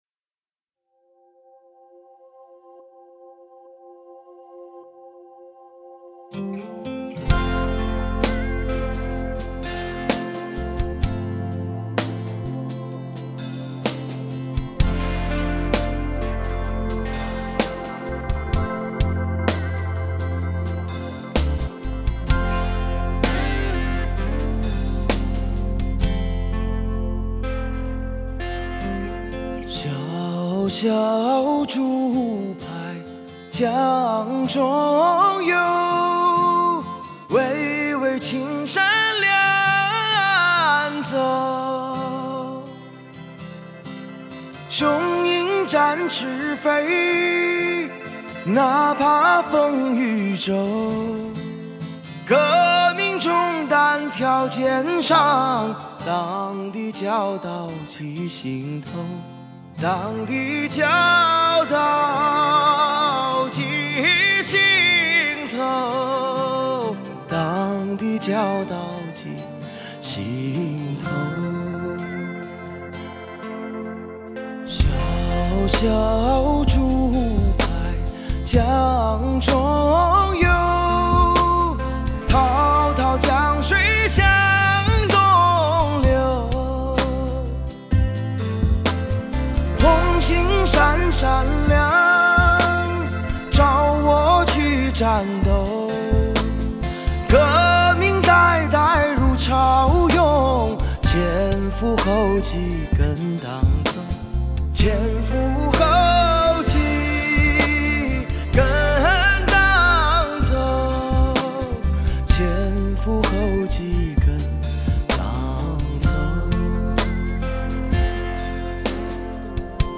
翻唱革命时期经典红色歌曲的专辑